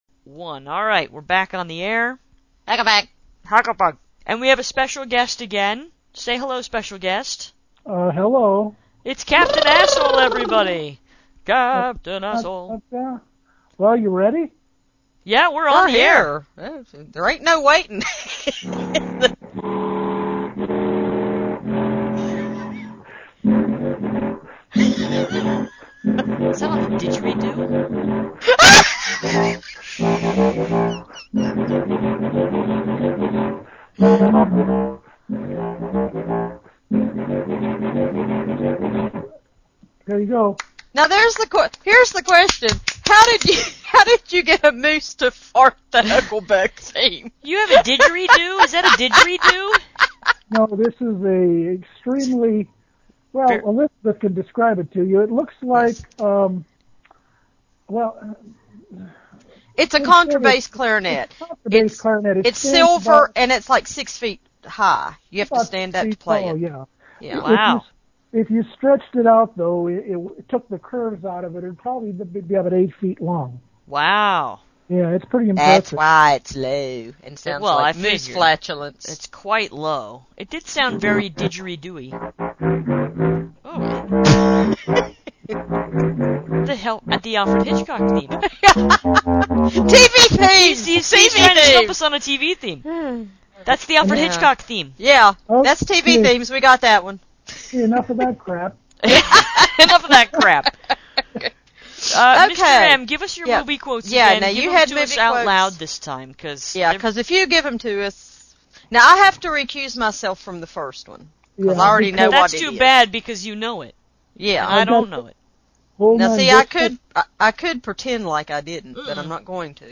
It’s also amazing how he can play a 8 ft long instrument from inside what sounded like a 55 gallon oil drum.